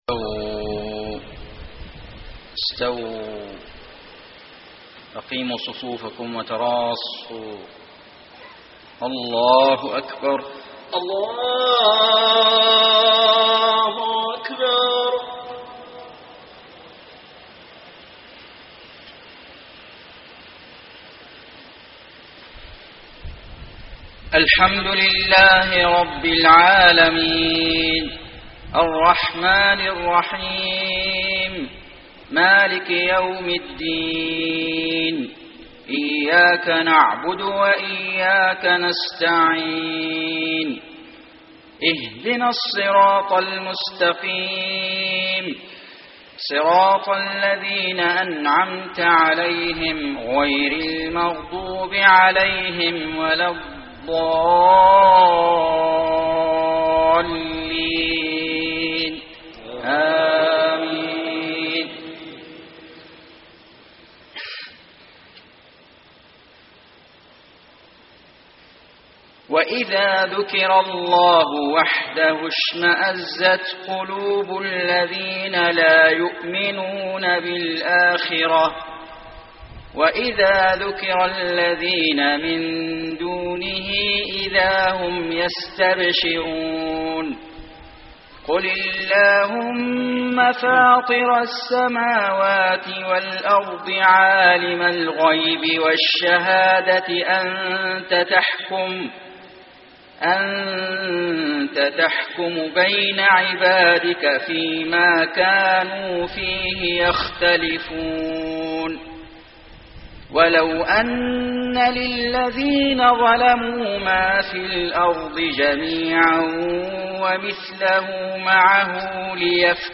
صلاة المغرب 1-7-1434هـ من سورة الزمر > 1434 🕋 > الفروض - تلاوات الحرمين